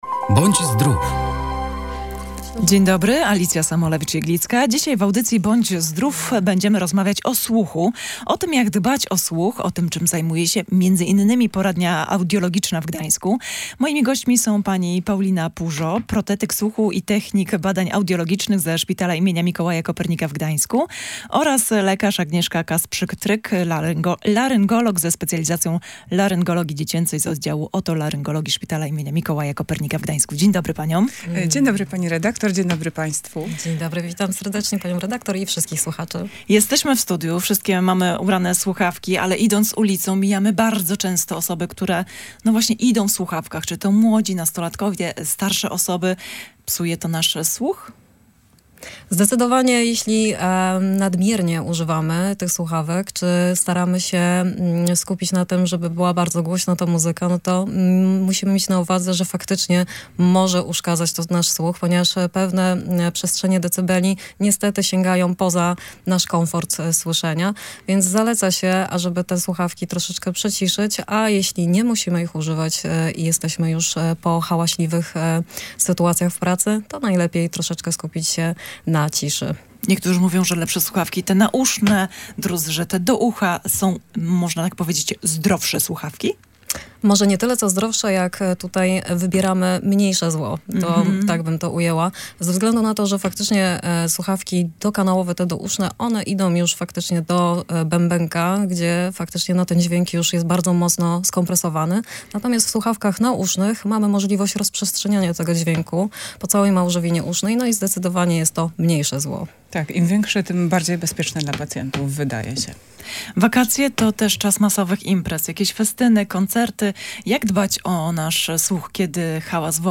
Posłuchaj audycji „Bądź Zdrów”: